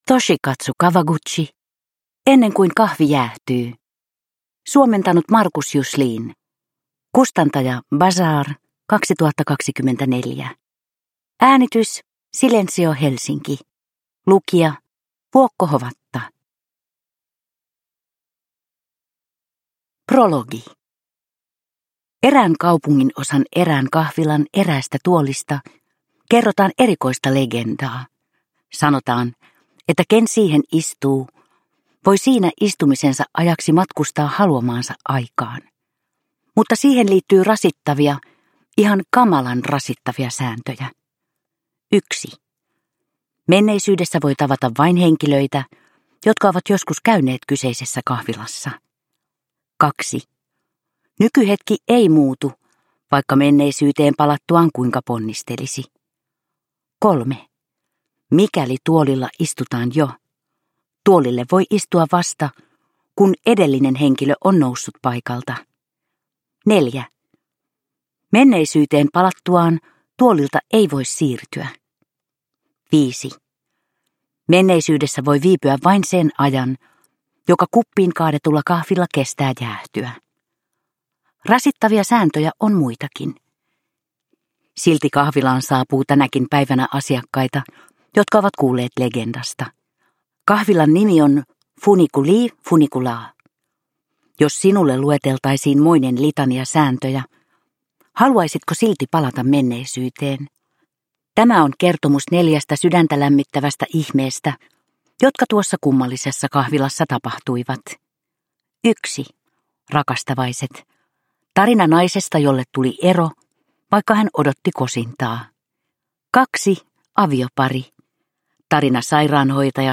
Ennen kuin kahvi jäähtyy – Ljudbok
Uppläsare